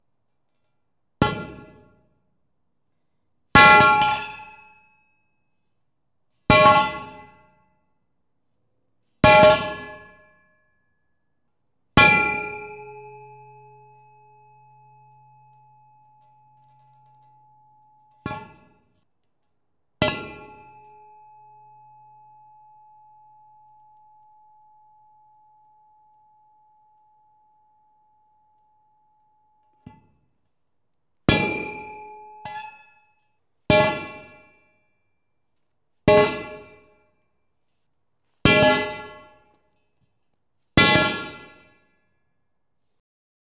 Medium_Steel_Pipe_On_Concrete_1
clang clank ding drop hit impact industrial metal sound effect free sound royalty free Sound Effects